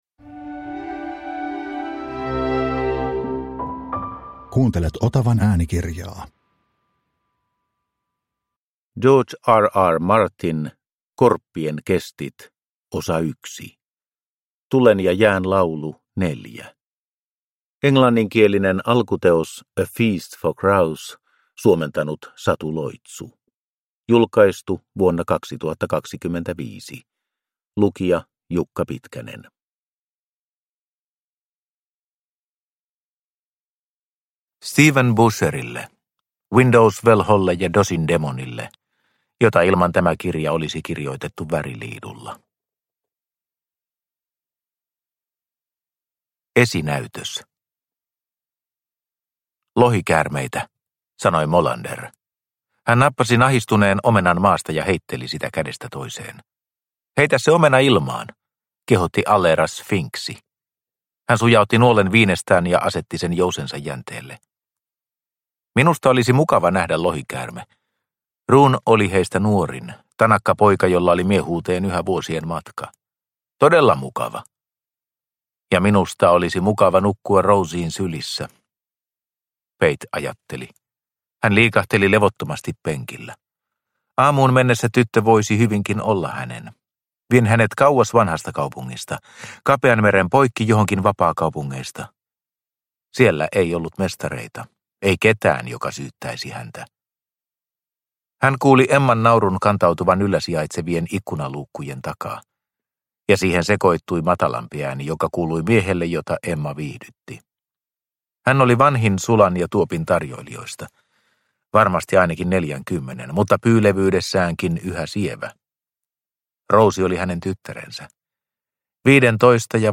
Korppien kestit 1 – Ljudbok
Äänikirjan ensimmäinen osa.